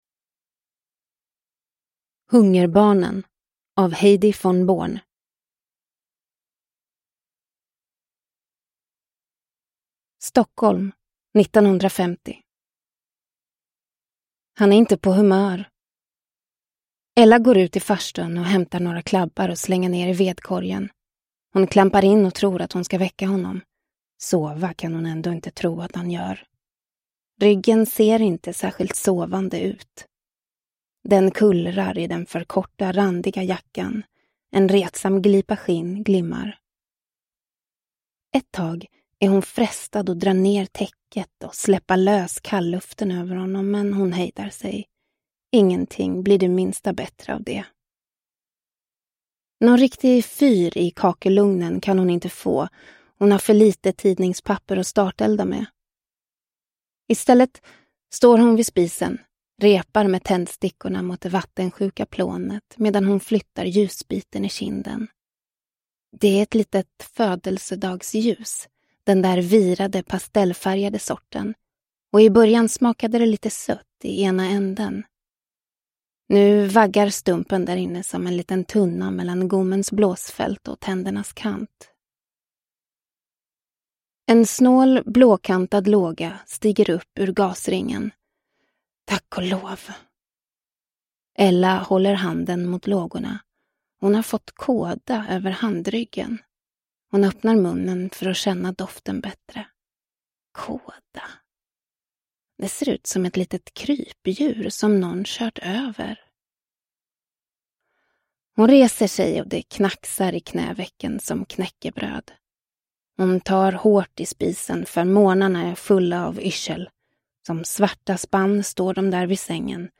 Hungerbarnen – Ljudbok – Laddas ner